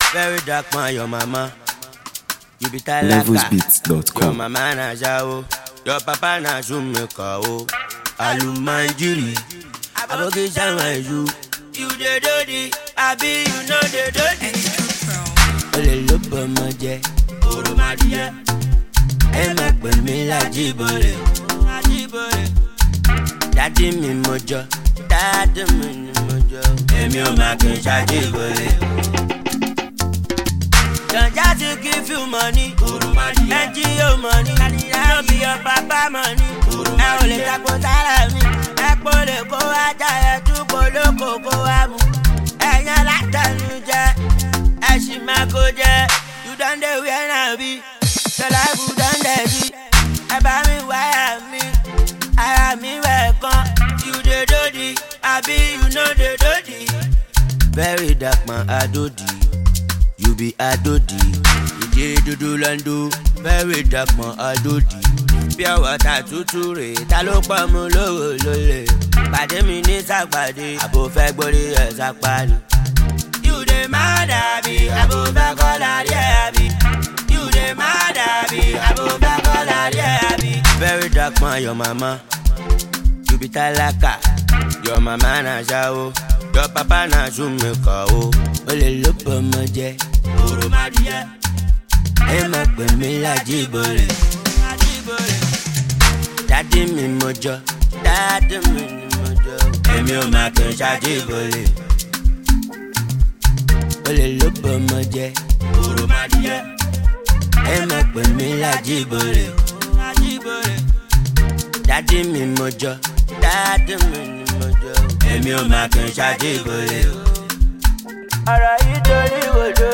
street-pop
Packed with energy and emotion